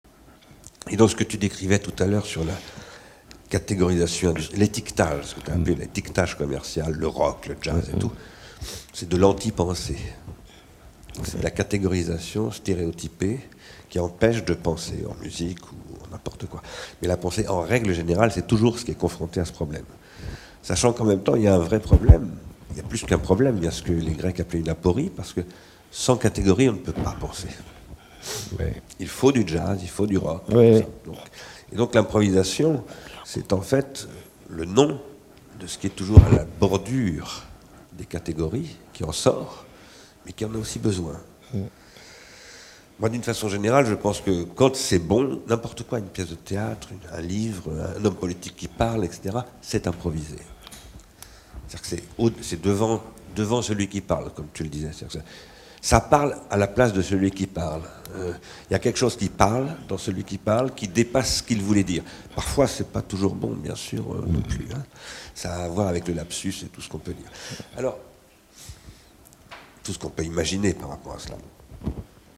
Bernard Stiegler, philosophe
b-stiegler-improvisation-et-categorisation.m4a